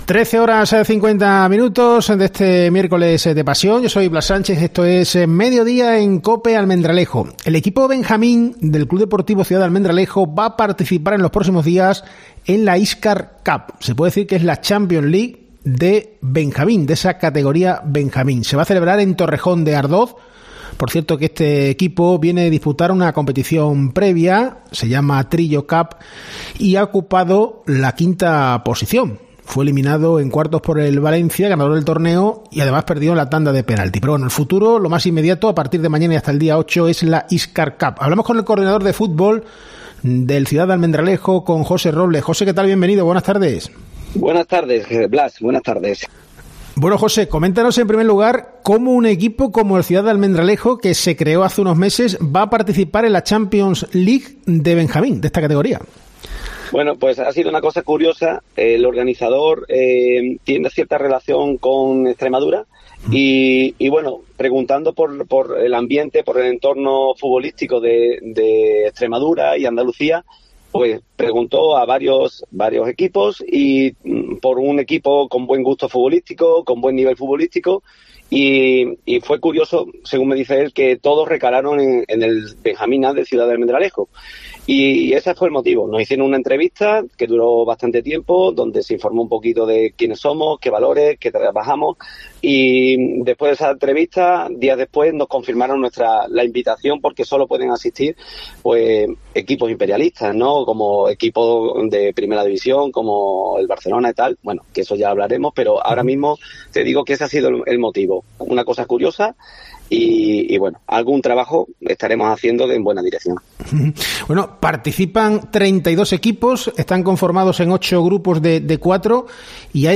En COPE hemos hablado con él.